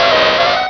Cri de Ponyta dans Pokémon Rubis et Saphir.